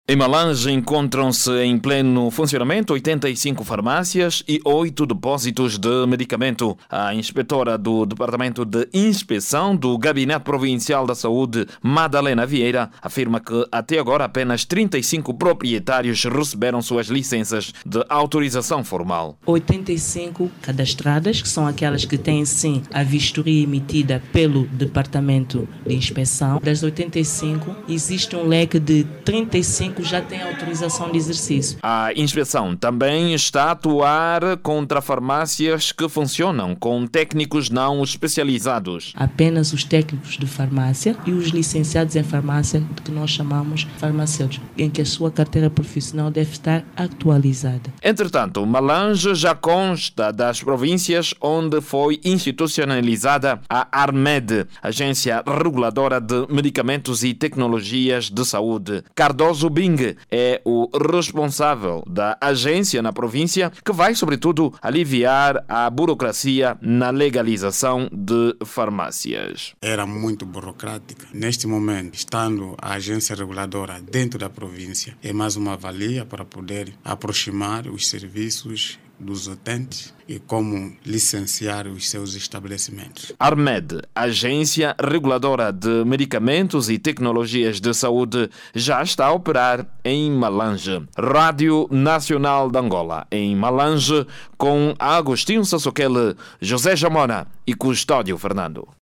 A legalização de farmácias em Malanje, preocupa a inspecção de saúde na província. A inquietação foi manifestada durante a efectivação da Agência Reguladora de Medicamentos e Tecnologia de Saúde na província. Ouça no áudio abaixo toda informação com a reportagem